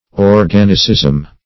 Organicism \Or*gan"i*cism\, n. (Med.)